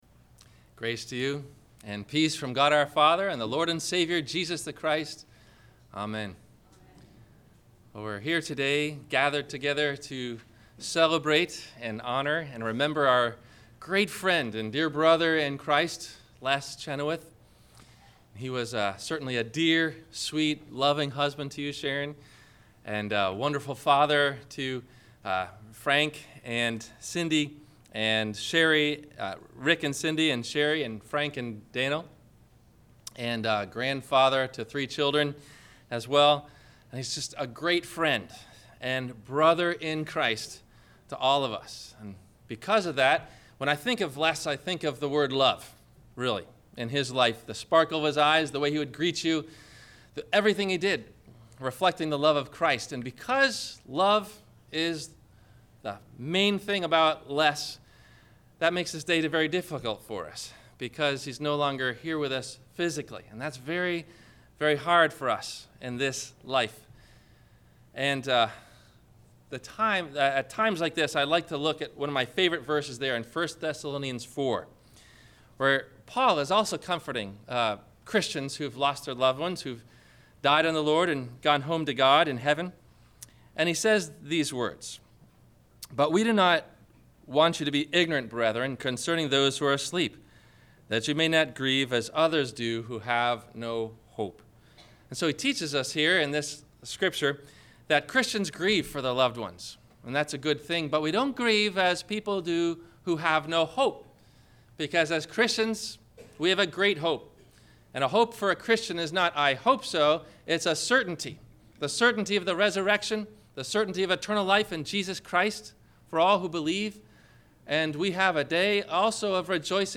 Memorial